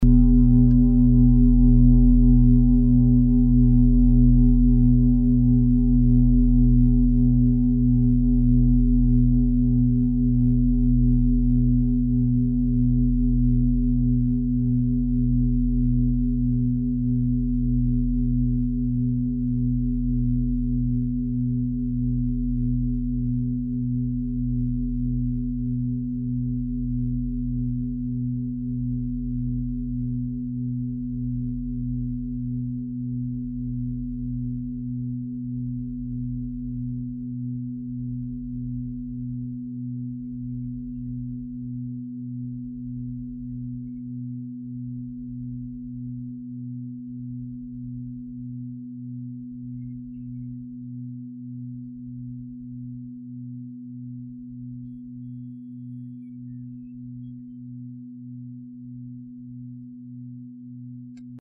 Fuß-Klangschale Nr.8
Klangschale-Durchmesser: 55,8cm
Diese große Klangschale wurde in Handarbeit von mehreren Schmieden im Himalaya hergestellt.
Oktaviert man diese Schwingung viermal, hört man sie bei 125,28 Hz. Auf unserer Tonleiter hört man sie nahe beim "H".
fuss-klangschale-8.mp3